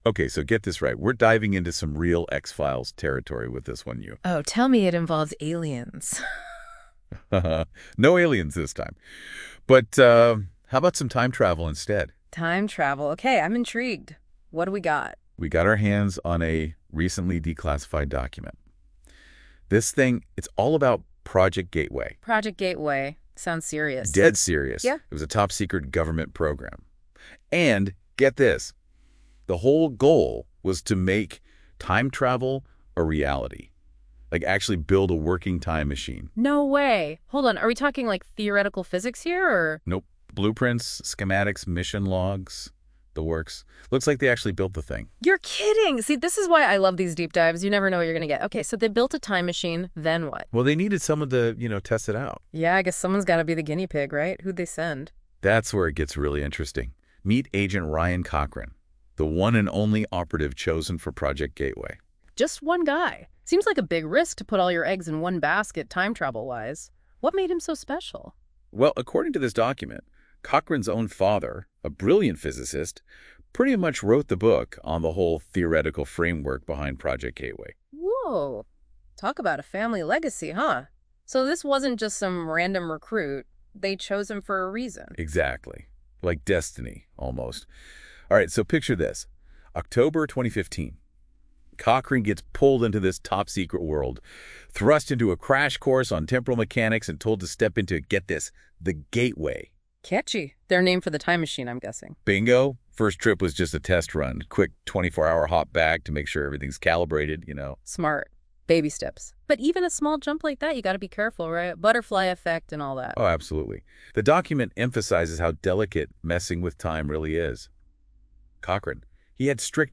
________________________________________________ Listen to a short discussion about Assassinating Yesterday !